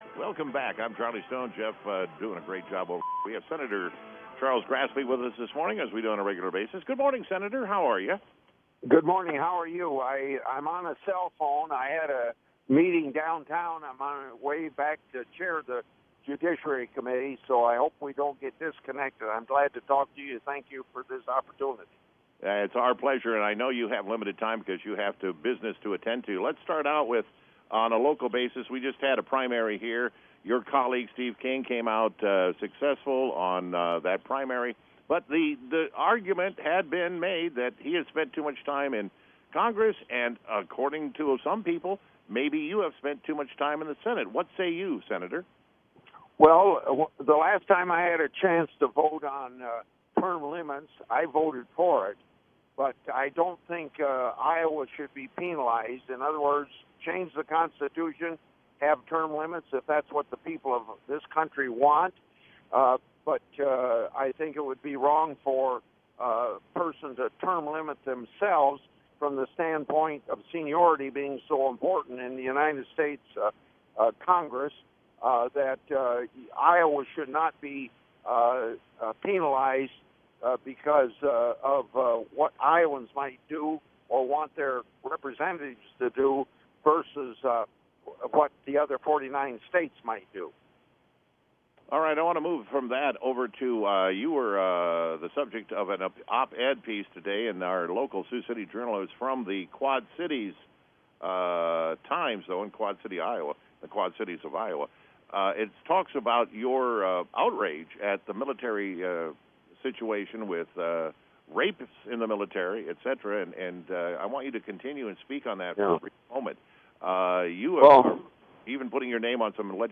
Public Affairs Program, 6-9-16 KSCJ.mp3